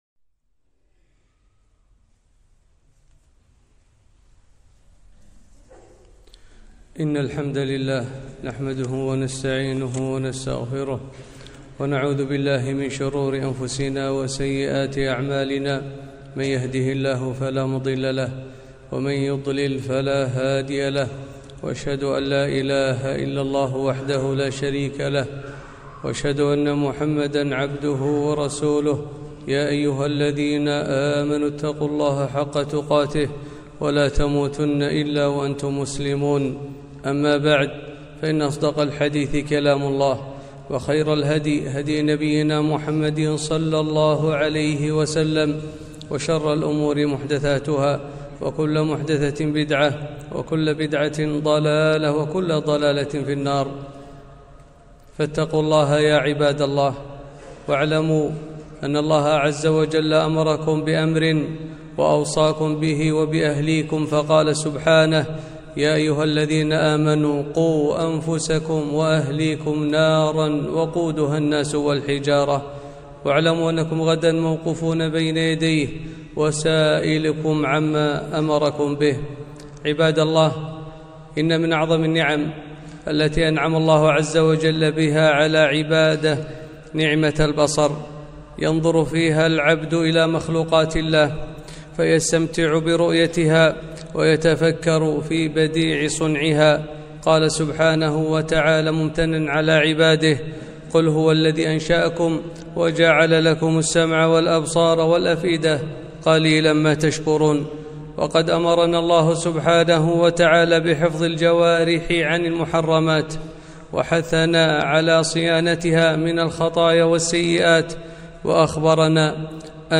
خطبة - غض البصر